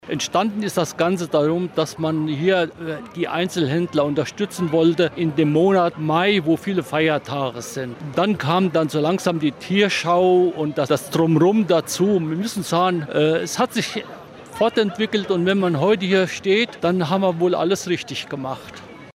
hat sich für uns im Radio Siegen-Interview an die Anfänge des Marktes erinnert.